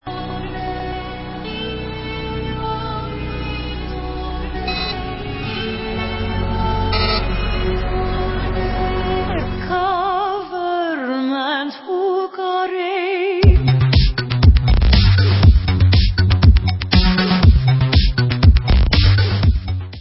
sledovat novinky v kategorii Dance
Pop